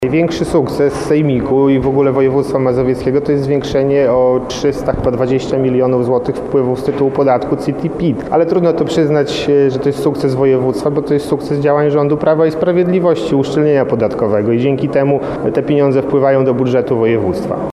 Jak wskazuje radny Prawa i Sprawiedliwości – Krzysztof Tchórzewski, większe dochody województwa nie są sukcesem obecnego zarządu Mazowsza.